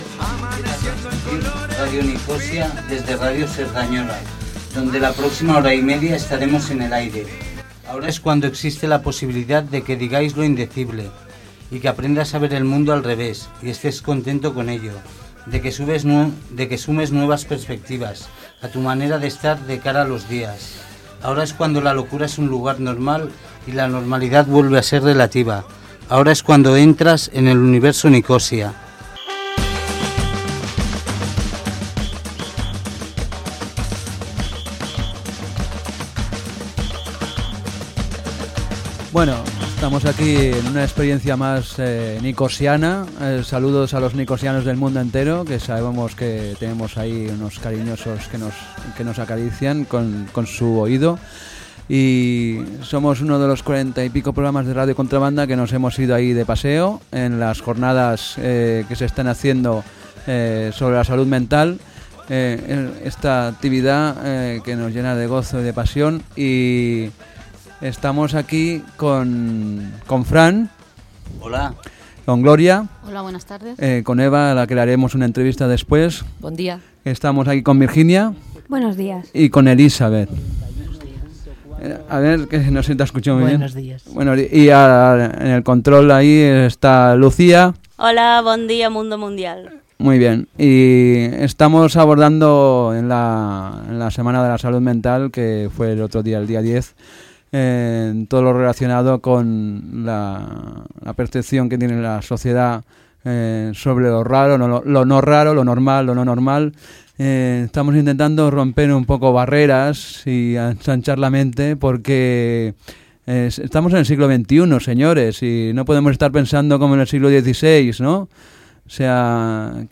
Programa realitzat per l'equip de Radio Nikosia de Contra Banda FM als estudis de Cerdanyola Ràdio coincidint amb la celebració de la Setmana de la Salut Mental a Cerdanyola.